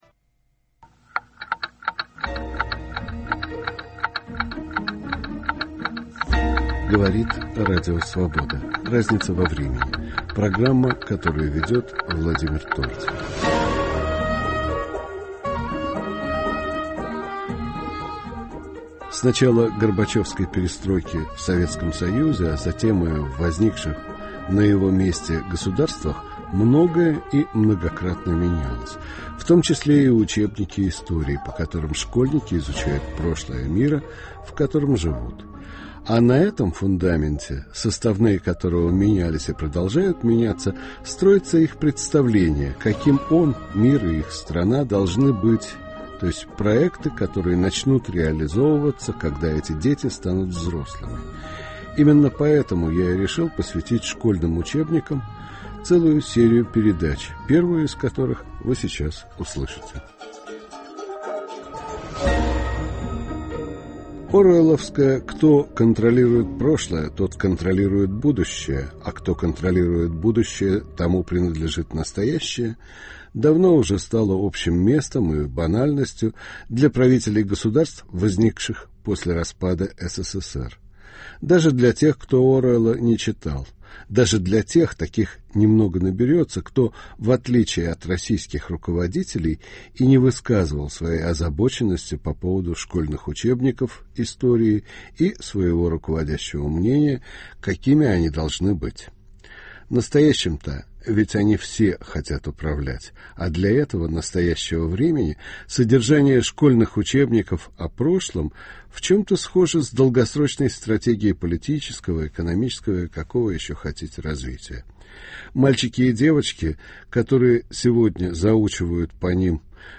Первая передача нового цикла " о школьных учебниках истории. В ней участвуют доктора исторических наук